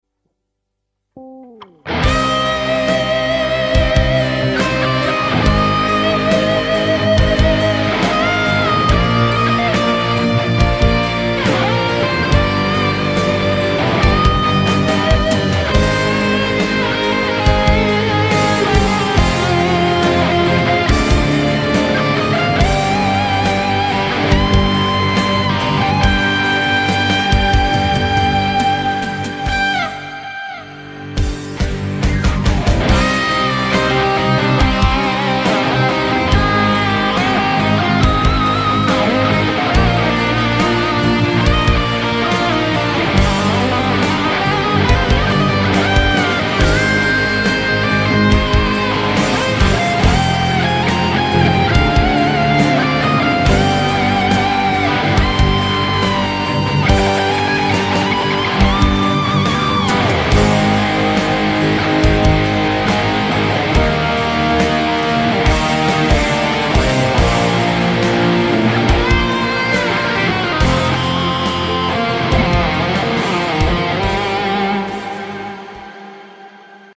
Guitar Solo Cover